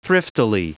Prononciation du mot thriftily en anglais (fichier audio)
Prononciation du mot : thriftily